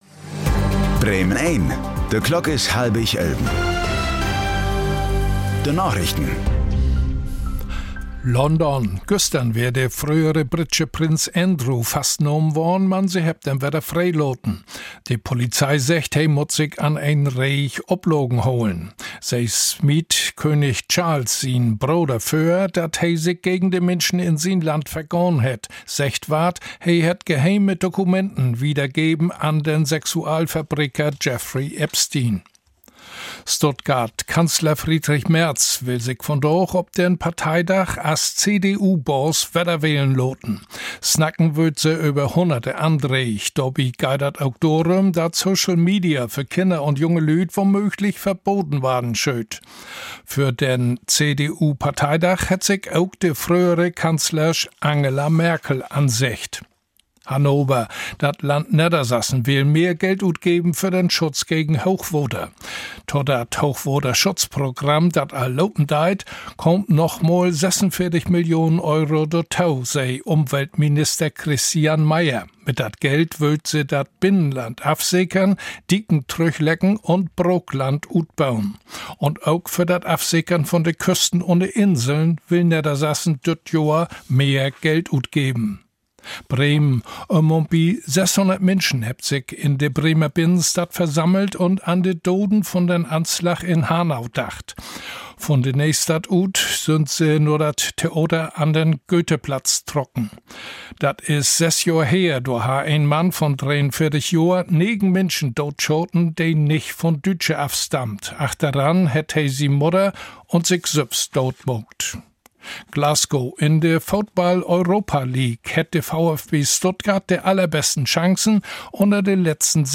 Plattdüütsche Narichten vun'n 20. Februar 2026